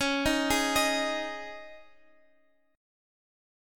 Dbsus2 chord